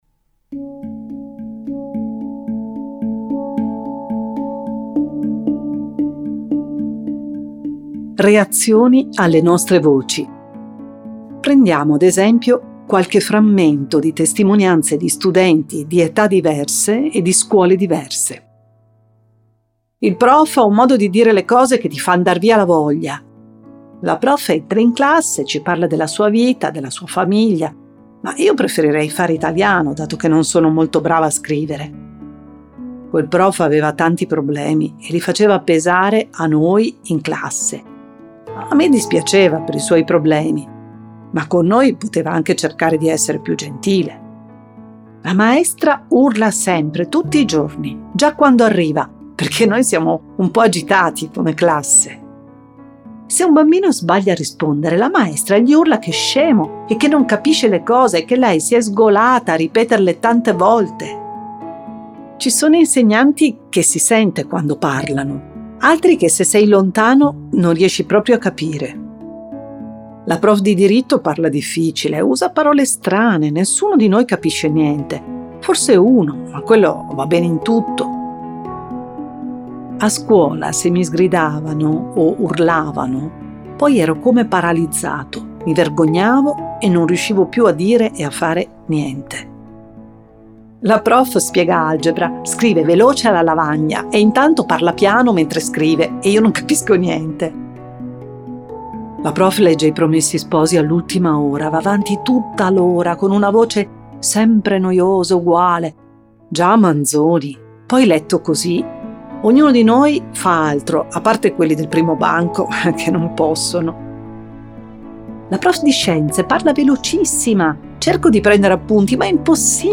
1 – Audiolibro – La voce e i suoi colori